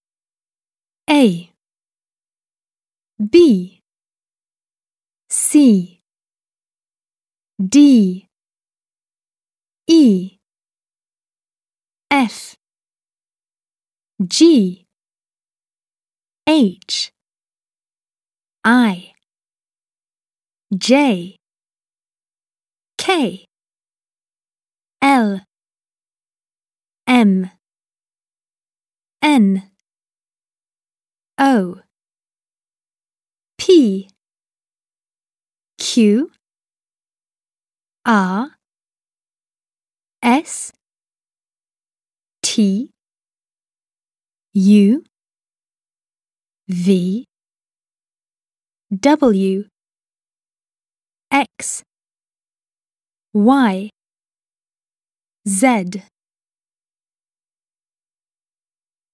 • Prononciation des lettres